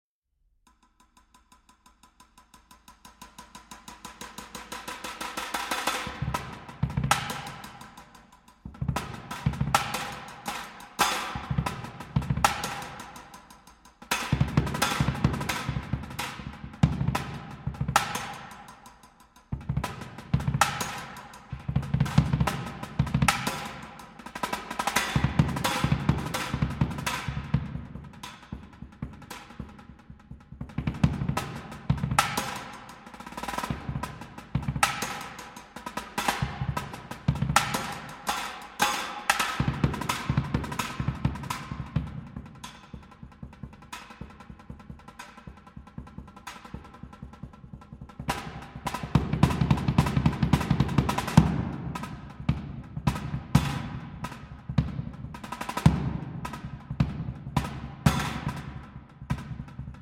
Marimba
Vibraphone
Timpani